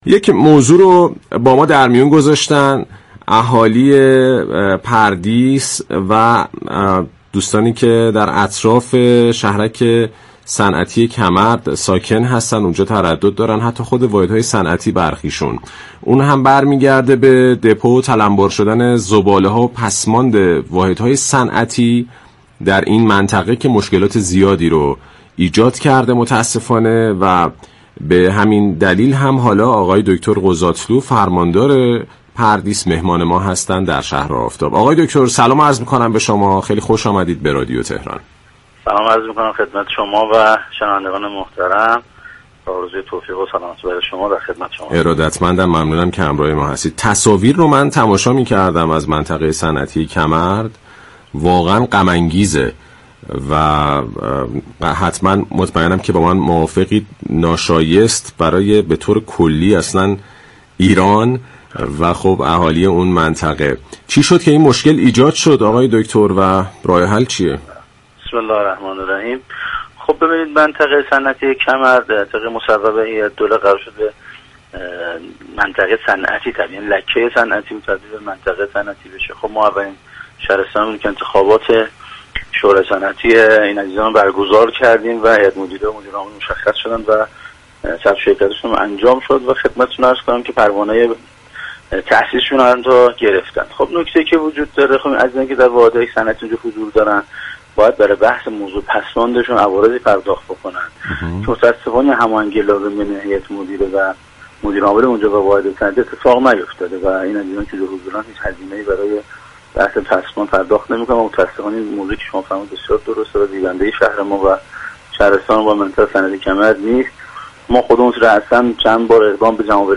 به گزارش پایگاه اطلاع رسانی رادیو تهران، محسن قضاتلو، فرماندار پردیس در گفت و گو با «شهر آفتاب» اظهار داشت: تولیدكنندگان صنعتی مستقر در منطقه صنعتی «كمرد» برای مدیریت پسماند باید عوارض پرداخت كنند ولی متاسفانه تاكنون در این حوزه هماهنگی لازم بین هیأت مدیره و واحدهای صنعتی صورت نگرفته است.